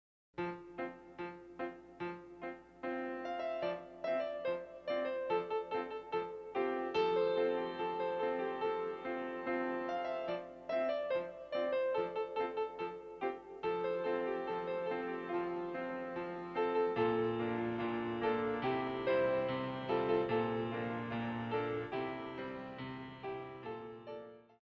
34 Piano Selections